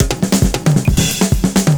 Index of /90_sSampleCDs/USB Soundscan vol.46 - 70_s Breakbeats [AKAI] 1CD/Partition A/27-133PERCS9